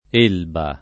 %lba] top. f. (Tosc.) — es.: l’isola d’E. (nell’uso loc., l’isola dell’E.), Campo nell’E., Rio nell’E. — generale nell’isola e in tutta la Tosc. la pn. con E- chiusa, regolare continuaz. dell’I- breve del lat. Ilva; freq. incertezze fuori della regione (per distanza geografica, quindi più scarso uso, e per attraz. di erba)